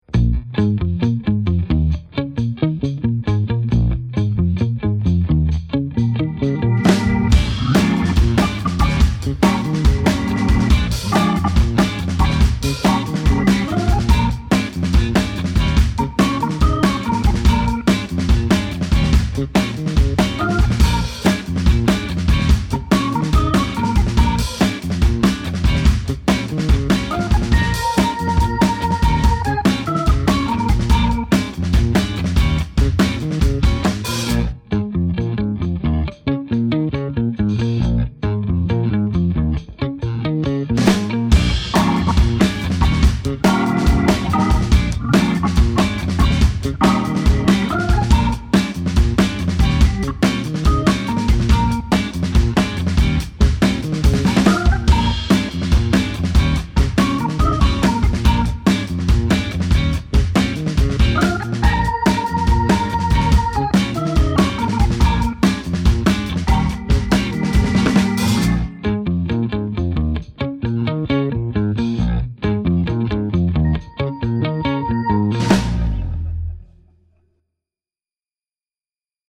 Vi spelar instrumental cool funk i 60/70-tals stil.
Kvartett; elgitarr, elbas, hammond orgel och livetrummor.